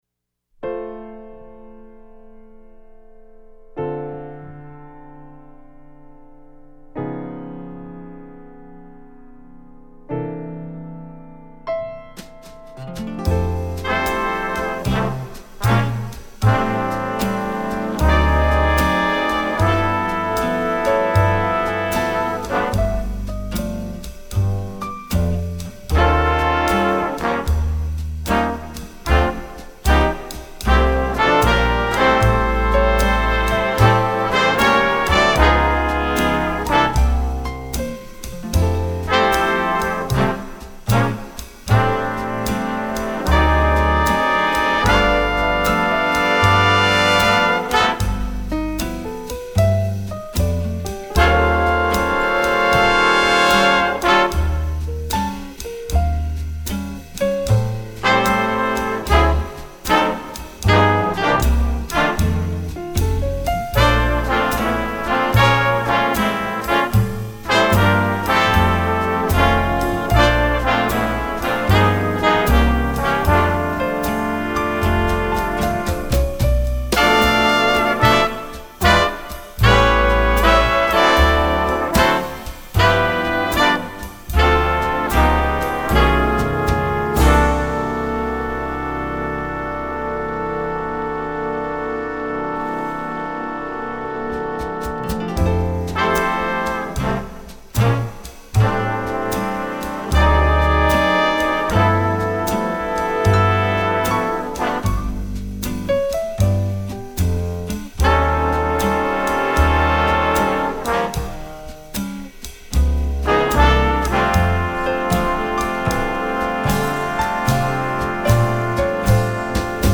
Instrumentation: jazz band